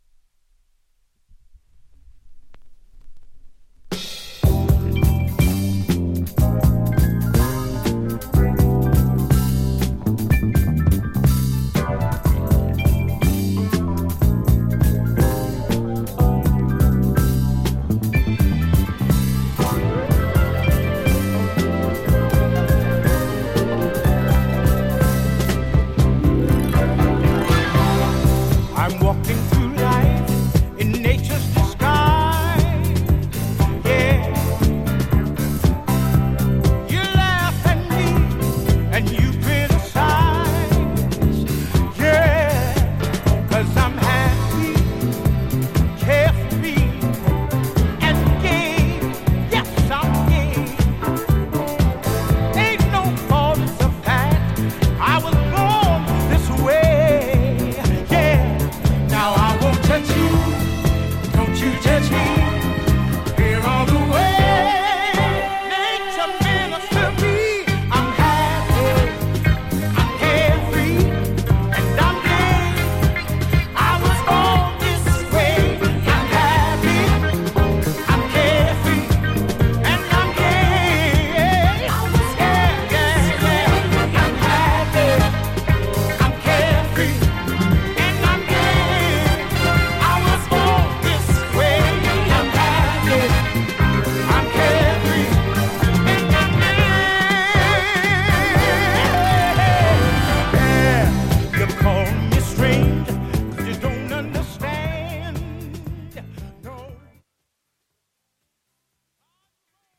ジャンル(スタイル) DISCO / GARAGE / DANCE CLASSIC / REISSUE